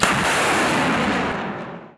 Tank mounted machinegun & canister missile launch
pop-missile-22khz.wav
pop_missile_22khz_130.wav